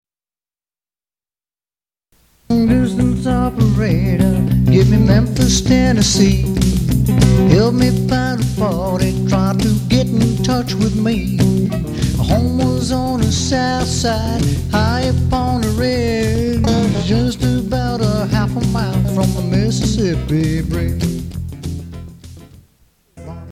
Rock 'N Roll Standards - Dance Tunes